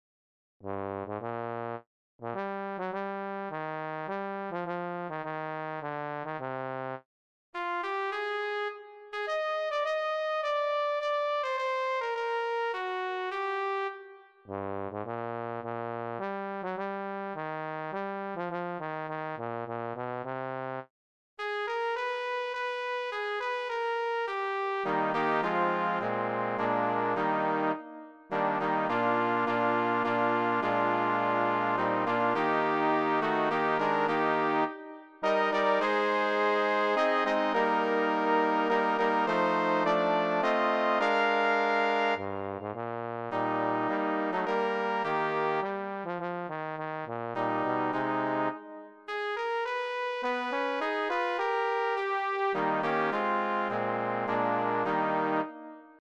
Cor mixt
midi
DOAR-PRIN-HAR-midi.mp3